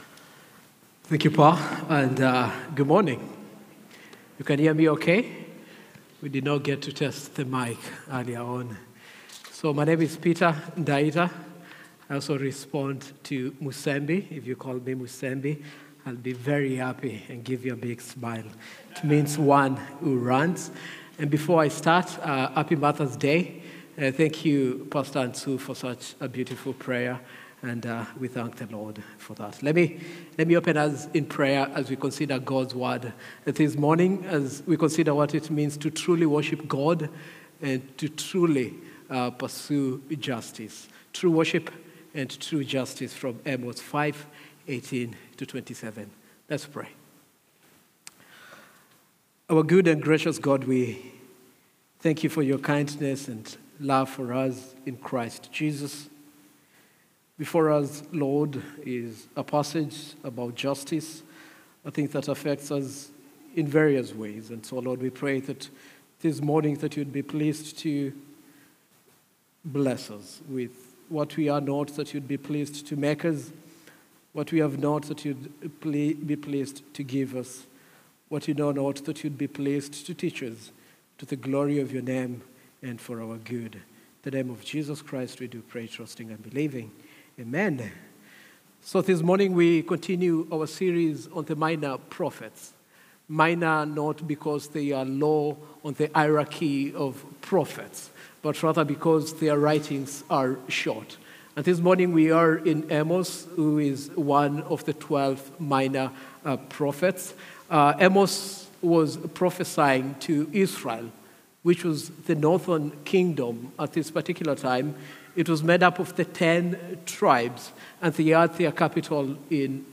A message from the series "Minor Prophets (WP)."